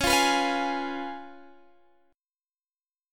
Db7 Chord
Listen to Db7 strummed